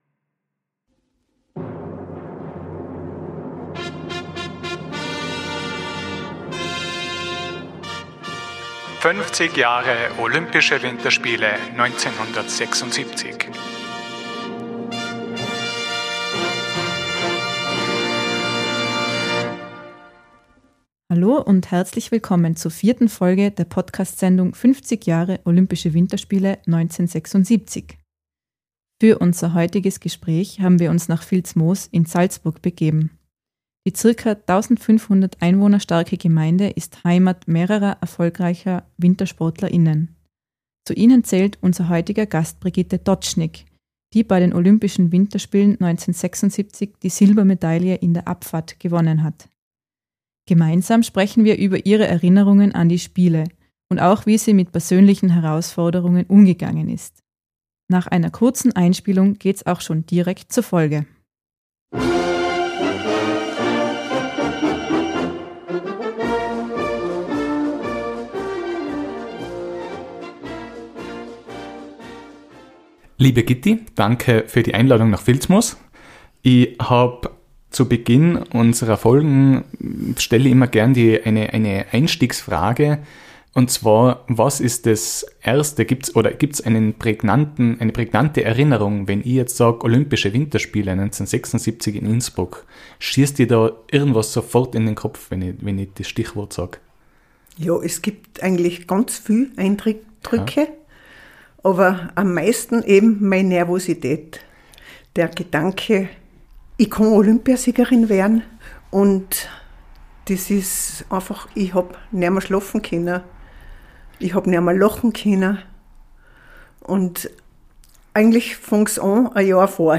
Für unser heutiges Gespräch haben wir uns nach Filzmoos in Salzburg begeben.